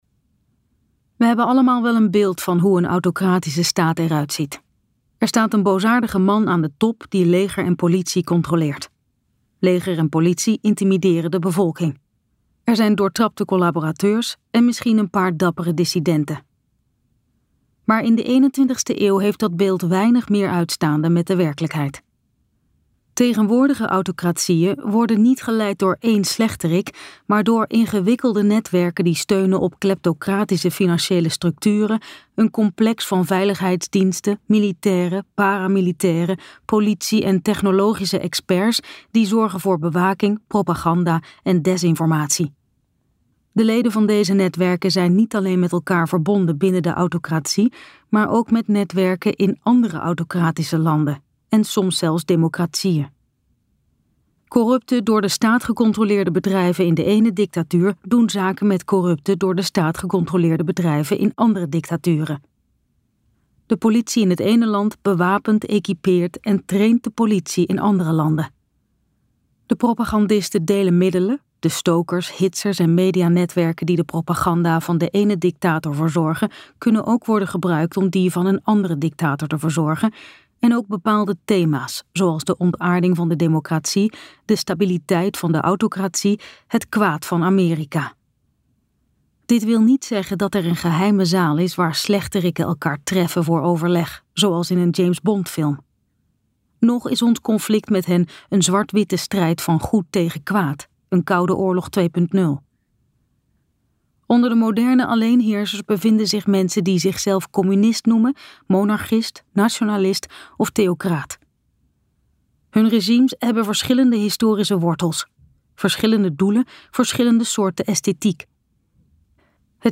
Ambo|Anthos uitgevers - Autocratie bv luisterboek